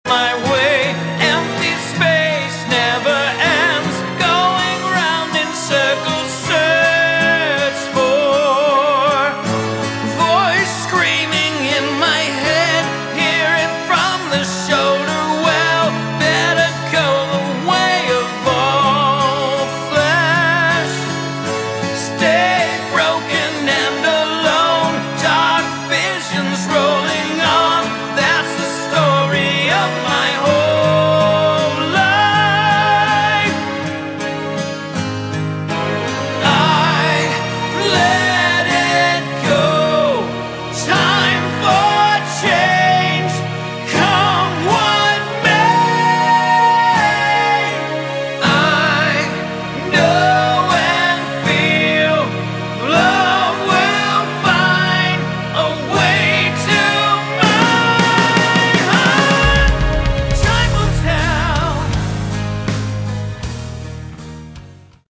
Category: Melodic Metal
lead and backing vocals
guitars, bass, keyboards
drums